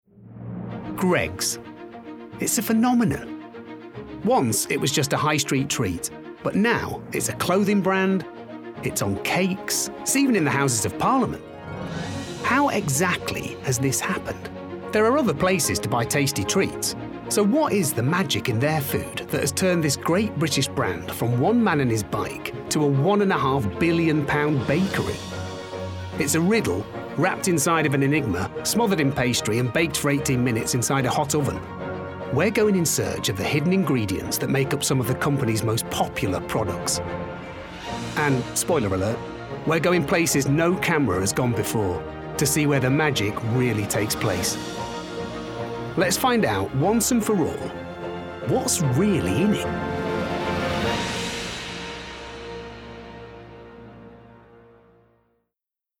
Documentary
Northern (English), Yorkshire, Lancashire, Standard English/RP, London/Cockney, American, Mancunian, Irish, Newcastle/Geordie
Actors/Actresses, Corporate/Informative, Natural/Fresh, Smooth/Soft-Sell, Character/Animation, Comedy, Upbeat/Energy, Mature/Sophisticated